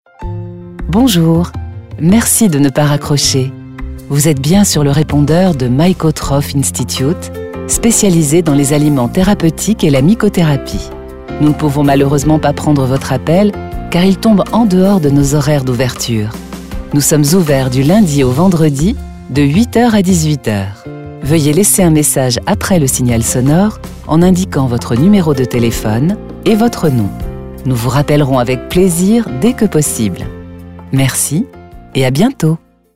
Telefonansage Französisch
Aber natürlich! Gerade eben durften wir ein paar schöne Ansagen für unseren Kunden MykoTroph produzieren.